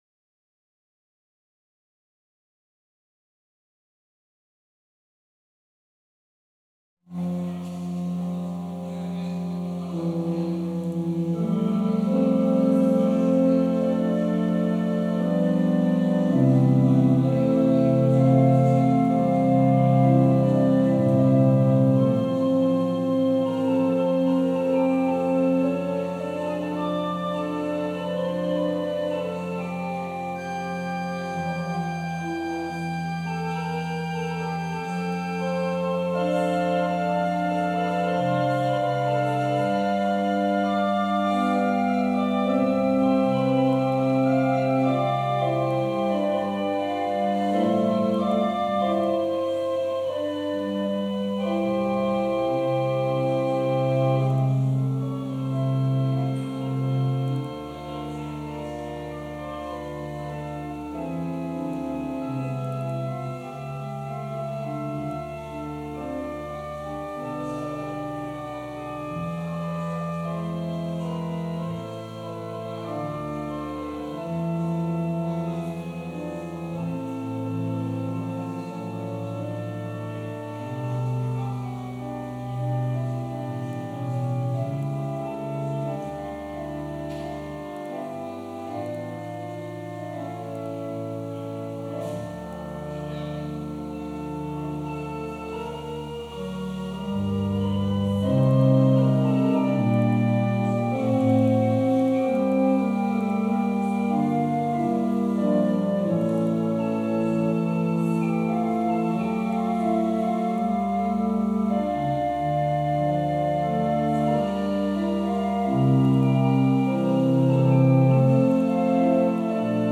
Passage: Matthew 16: 13-20 Service Type: Sunday Service Scriptures and sermon from St. John’s Presbyterian Church on Sunday